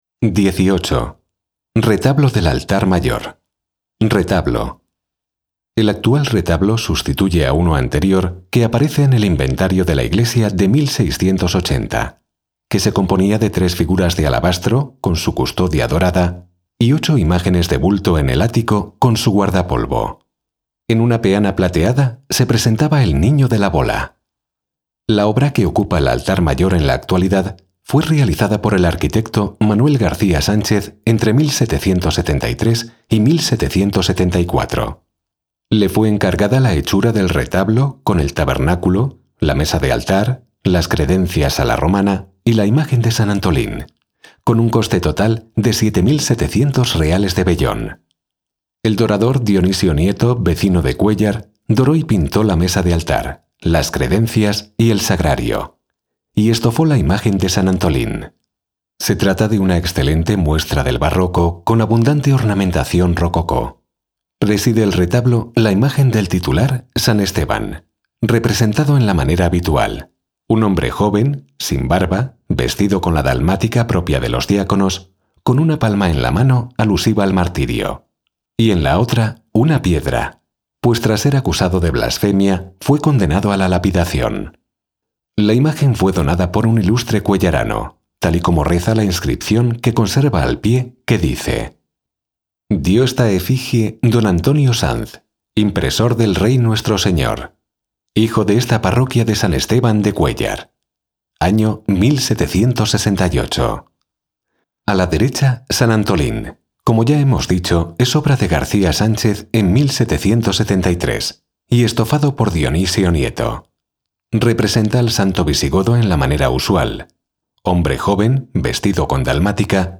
audio guías